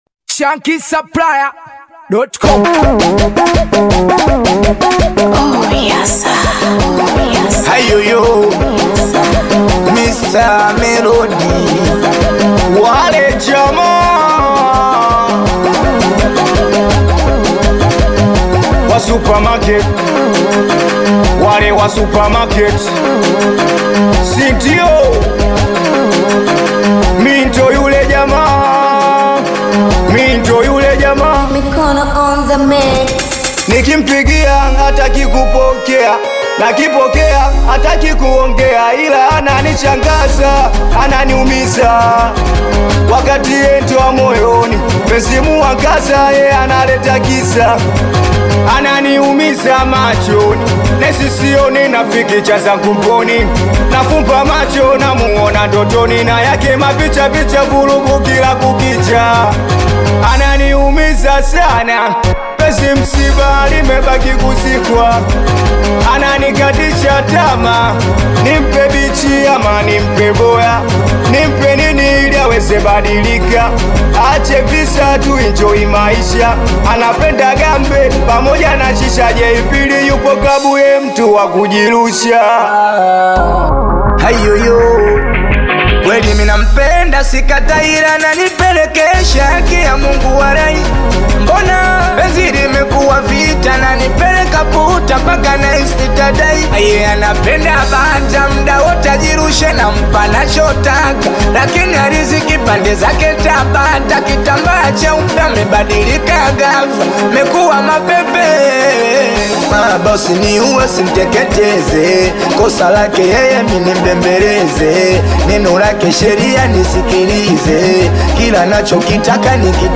smooth flow
powerful vocals
The infectious melodies and infectious energy